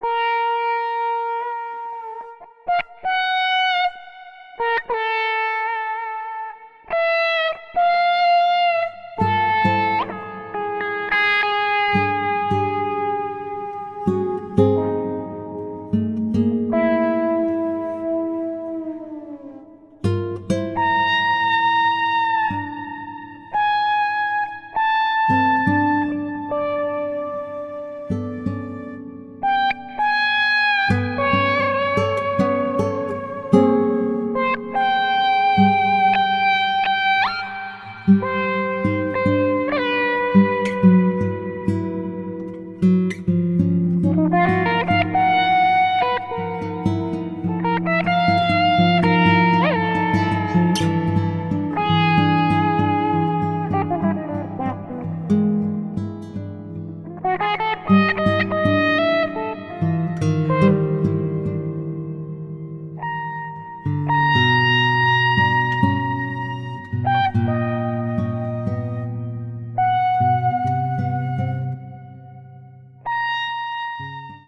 Electric guitar, Samples